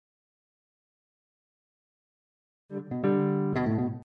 А вот с началом рендера, все-таки что делать? Такое ощущение, что фейд-ин какой-то есть.